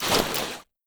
unprone.wav